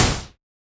Index of /musicradar/retro-drum-machine-samples/Drums Hits/Tape Path B
RDM_TapeB_SY1-Snr01.wav